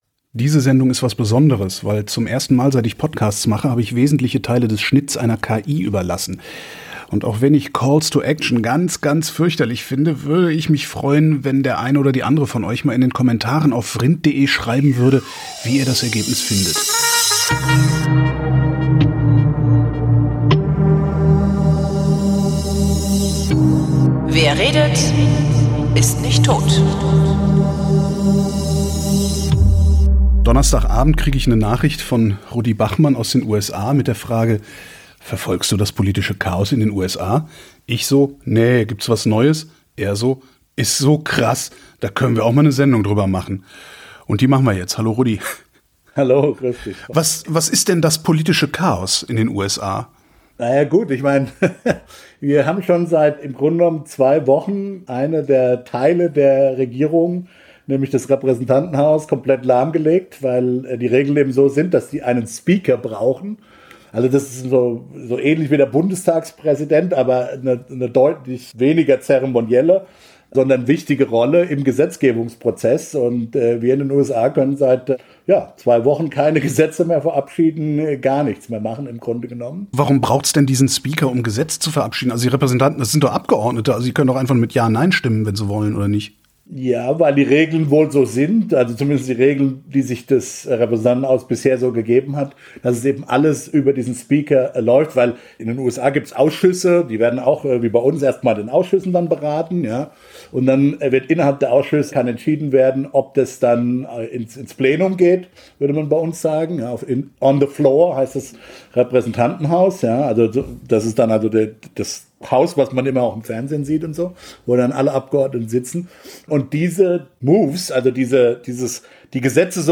Diese Sendung ist das erste Mal, dass ich wesentliche Teile des Schnitts von einer KI habe machen lassen, und mich interessiert sehr, wie ihr das Ergebnis findet.